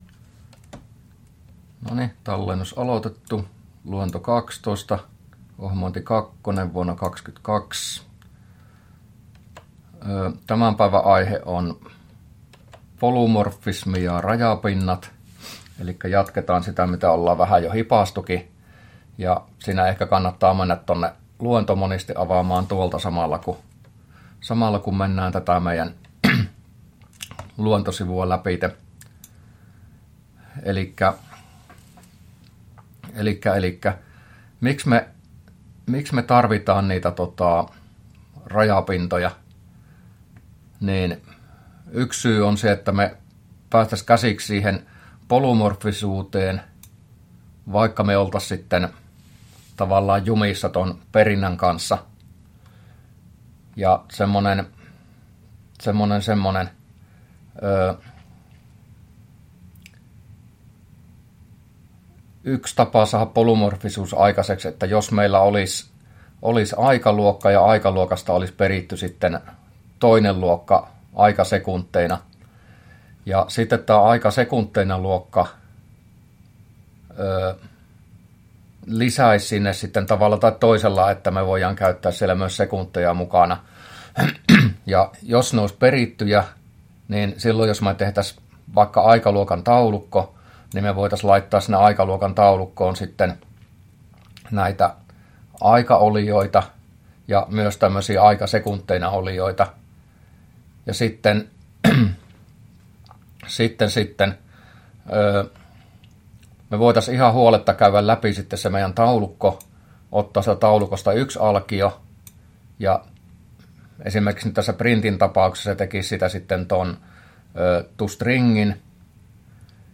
luento12a